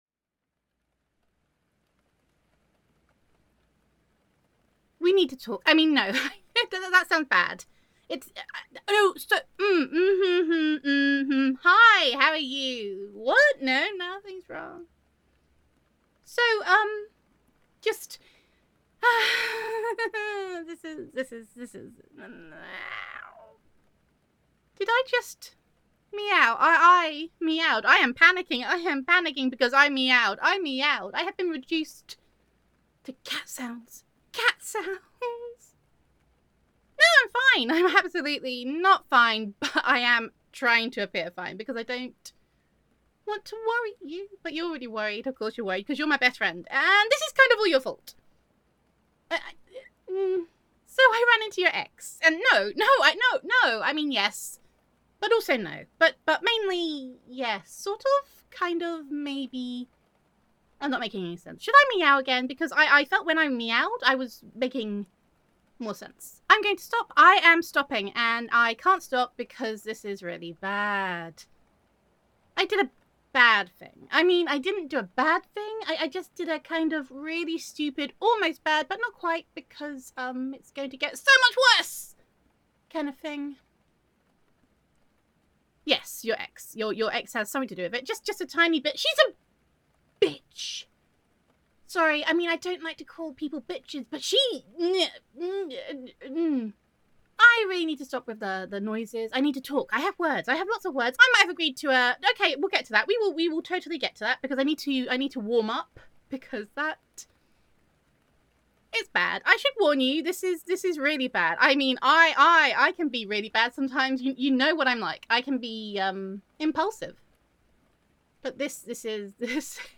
[F4A] Dance Like Nobody Is Watching (Even Though the Internet Will Be Watching) [Best Friend Roleplay][Friends to Lovers][Love Confession][Your Ex Is Evil][I Did a Bad Thing][I Need Your Help][Jealousy][Adorkable][Panicking][Awkward AF][Gender Neutral][Your Best Friend Accidentally Agreed to a Dance Off With Your Ex]